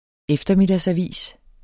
Udtale [ ˈεfdʌmedas- ]